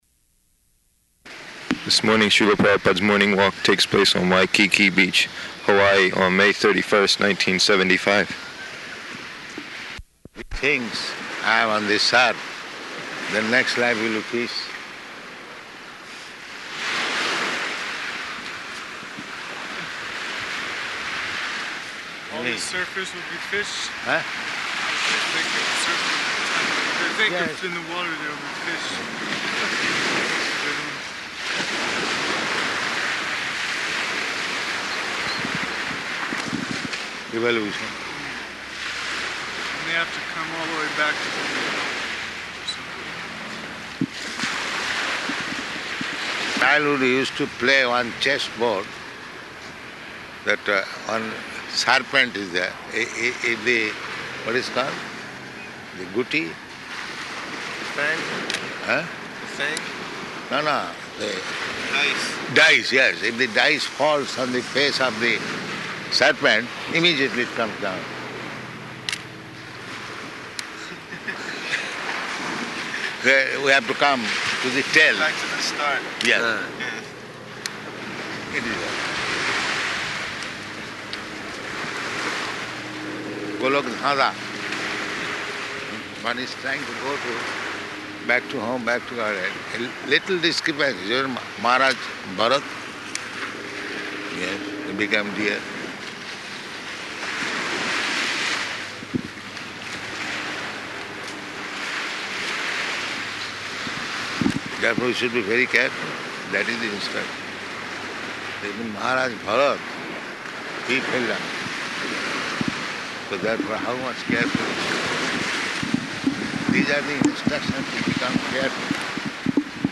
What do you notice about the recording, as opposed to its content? Location: Honolulu